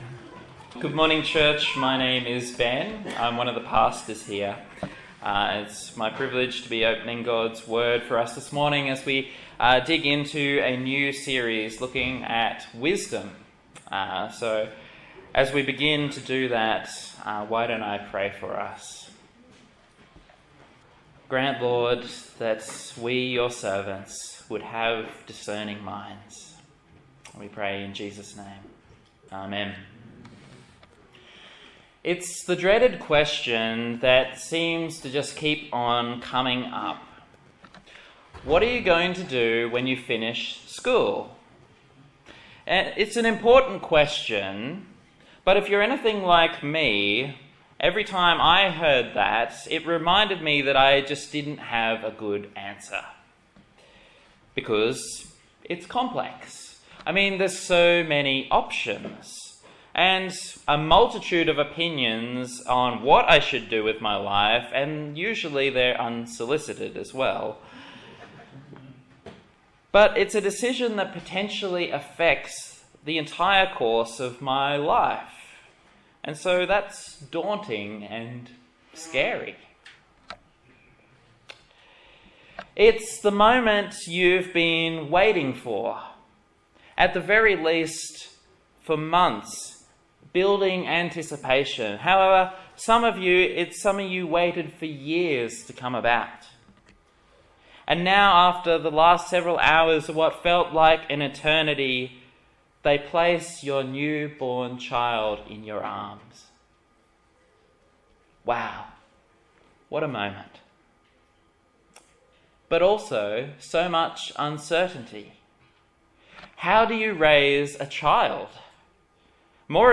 A sermon in the Proverbs Series on the Book of Proverbs
Proverbs Passage: Proverbs 1:1-7 Service Type: Morning Service